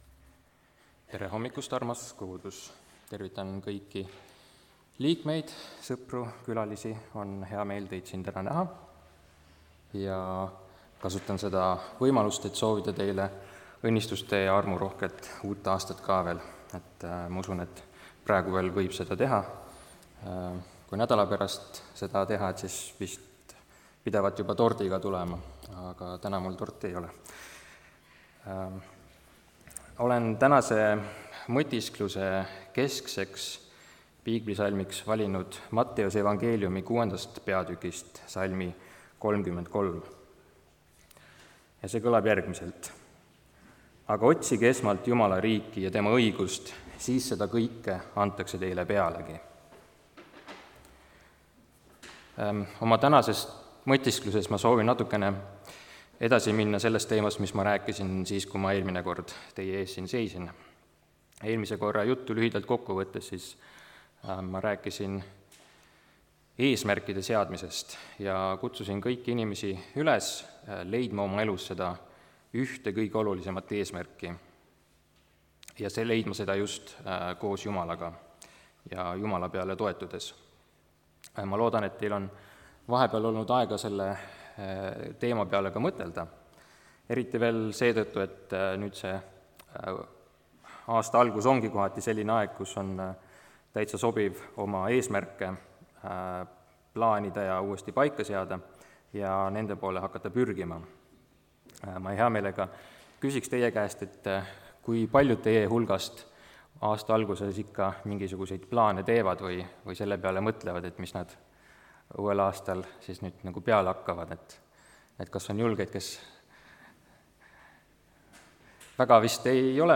(Tallinnas)
kategooria Audio / Jutlused / Teised